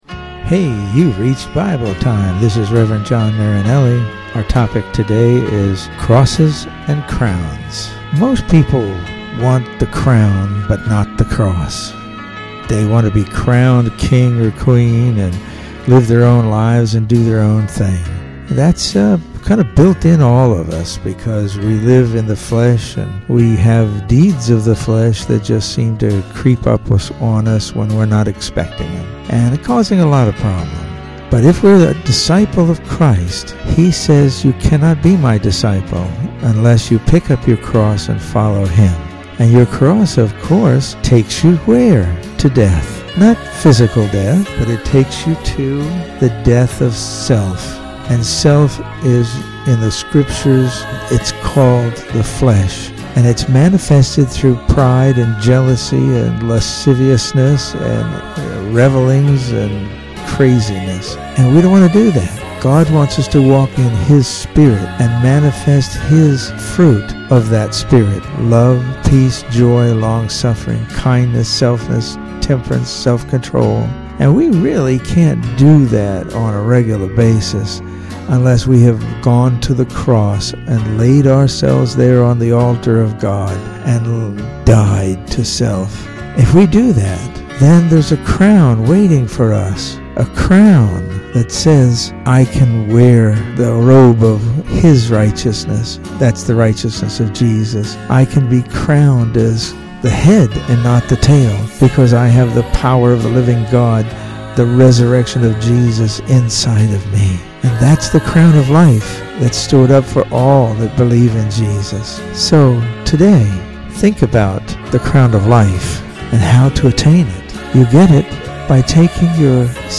Welcome to Bible Time. The audio files are all 3-6 minute devotional messages that are meant to encourage and